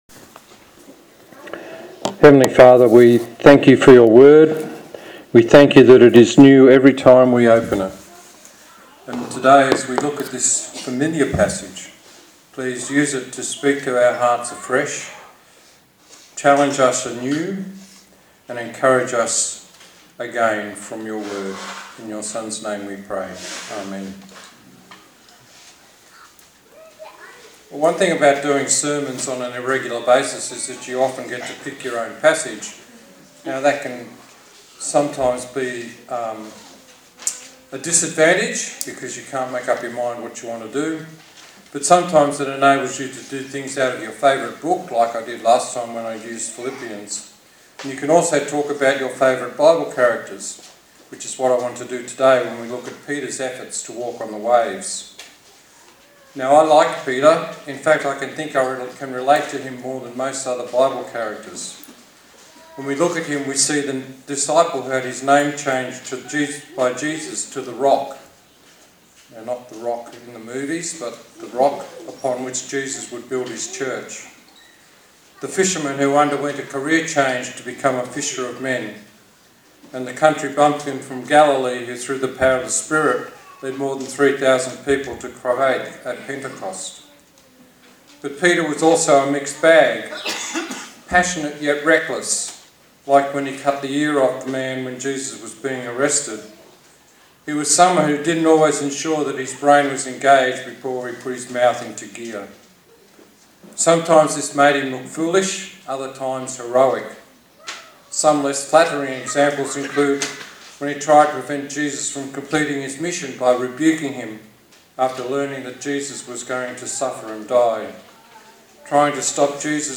Service Type: Sunday Morning A sermon on the book of Matthew